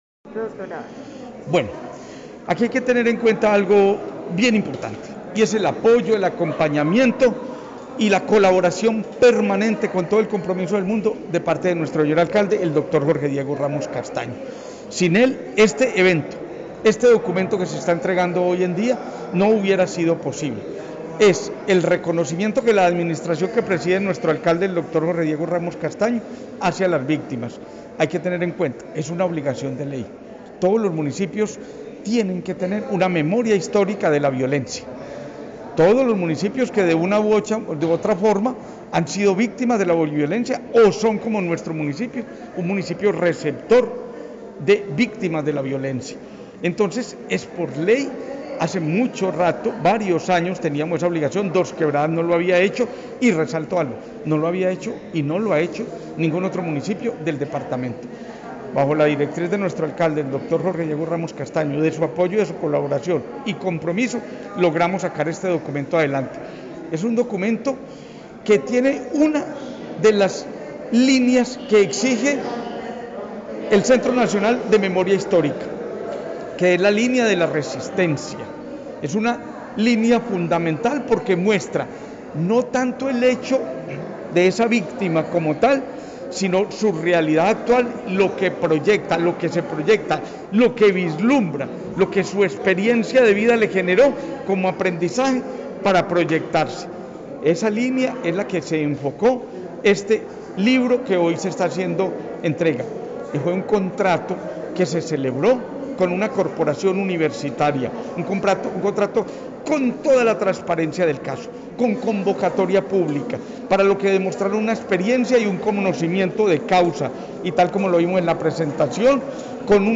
Escuchar Audio: Secretario de Gobierno, Juan Carlos Sepúlveda Montoya.